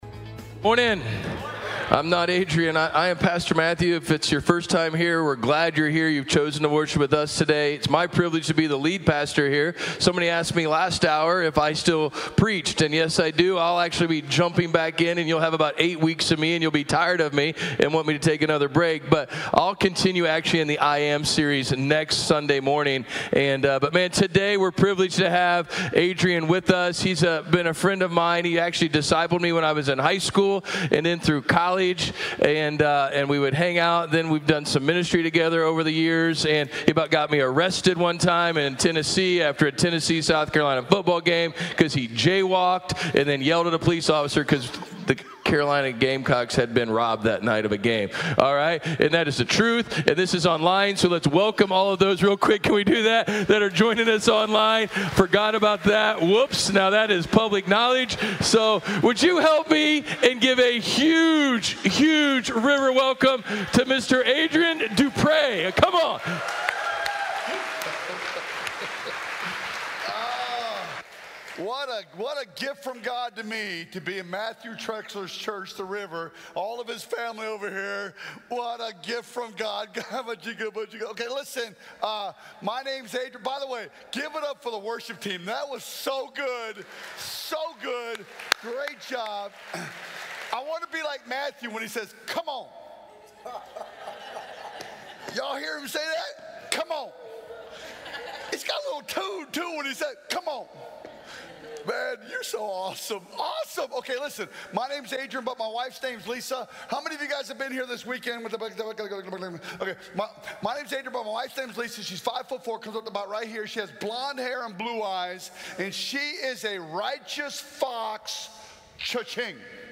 Message from the "I AM" sermon series at The River Church, Marion.